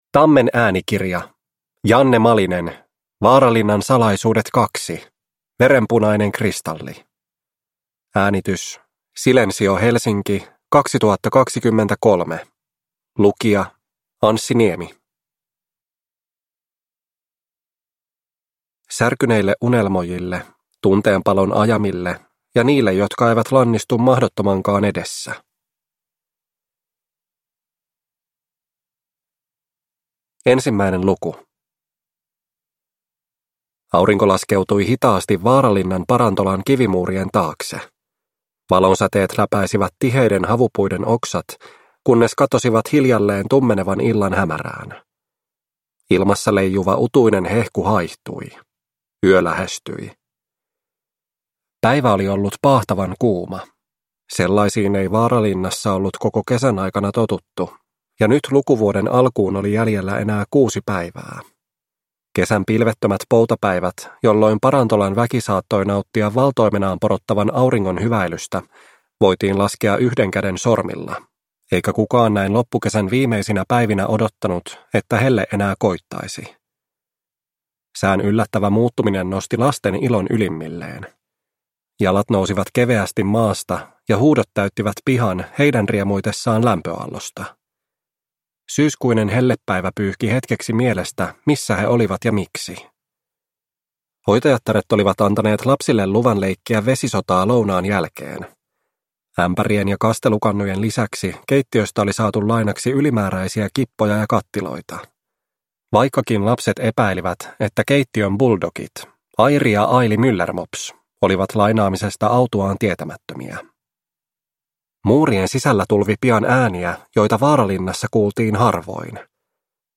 Waaralinnan salaisuudet 2. Verenpunainen kristalli – Ljudbok – Laddas ner